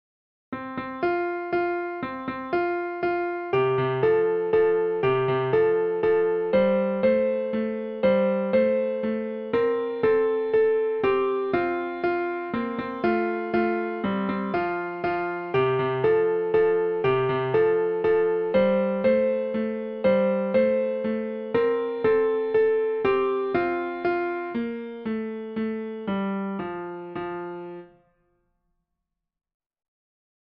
a children's song